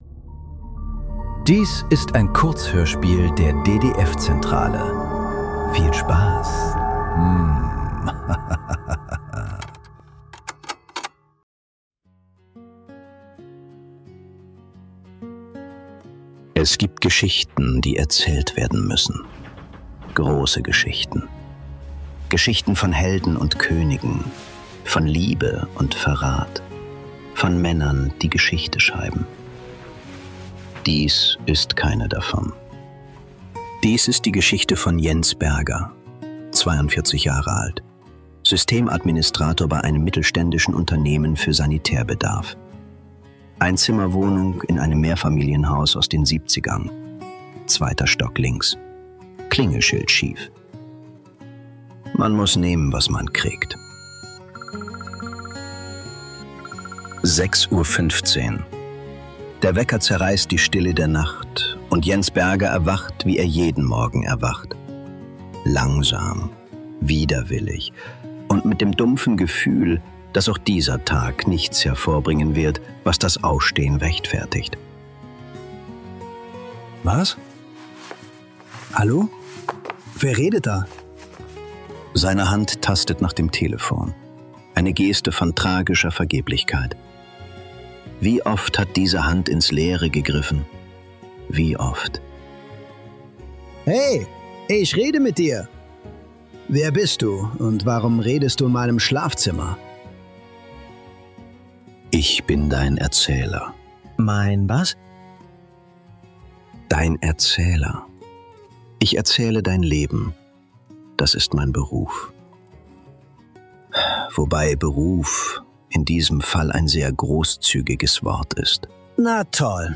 Erzähl mich nicht ~ Nachklang. Kurzhörspiele. Leise. Unausweichlich. Podcast